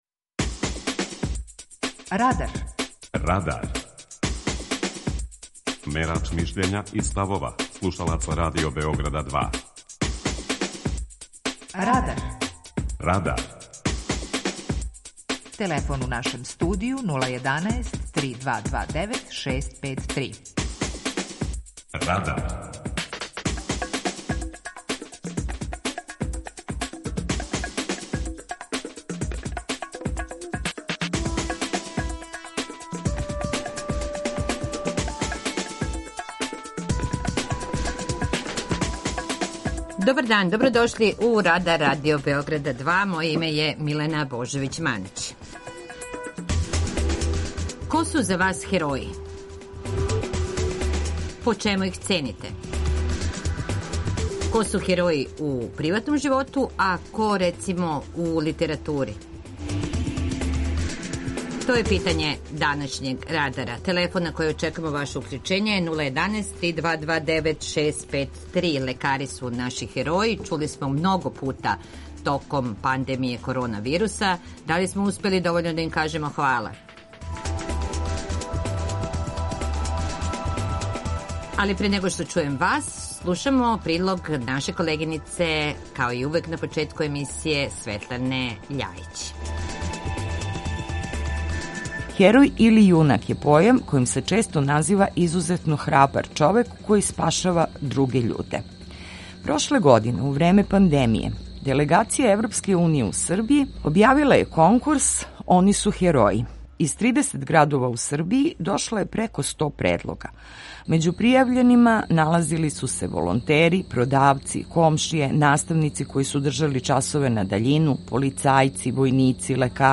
Питање Радара је: Ко је ваш књижевни ‒ животни херој? преузми : 18.47 MB Радар Autor: Група аутора У емисији „Радар", гости и слушаоци разговарају о актуелним темама из друштвеног и културног живота.